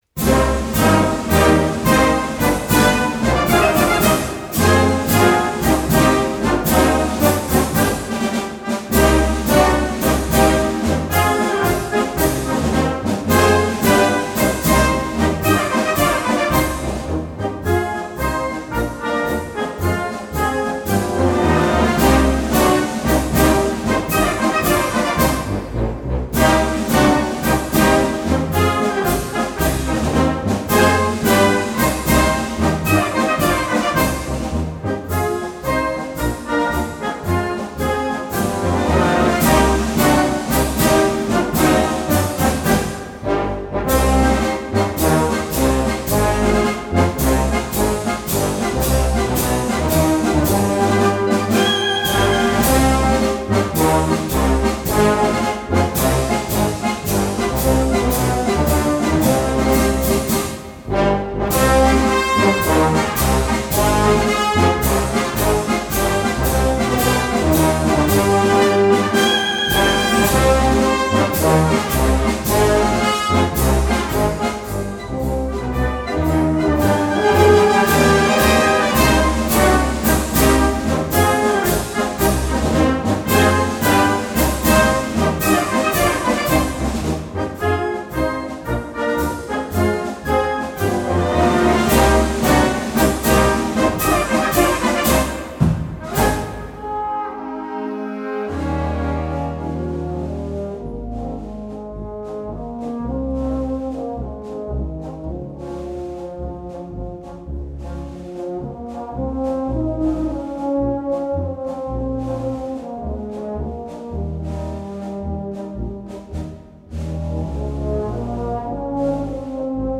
Gattung: Konzertmarsch für Blasorchester
Besetzung: Blasorchester